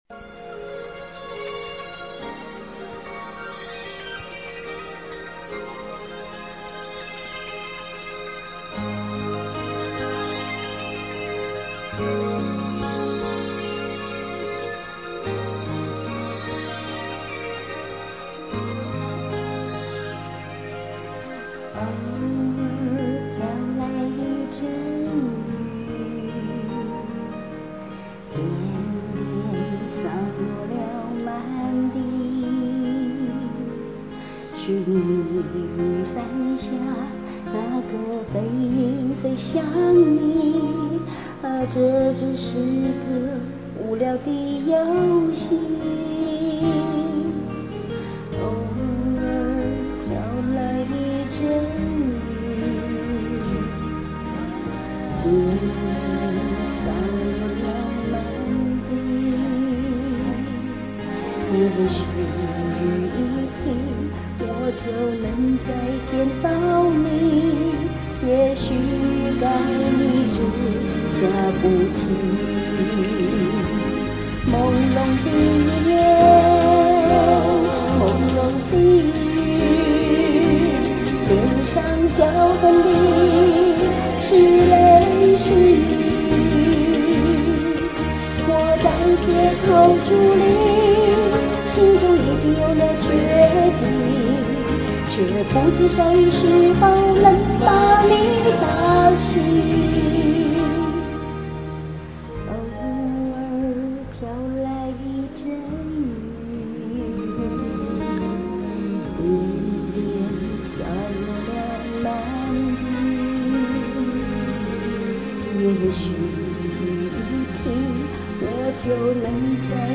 再發一首自戀翻唱囉~ ^0^
喜愛校園民歌的朋友應該都有印象吧?
聽得出歌聲裡充滿了感情
我只有手機錄音app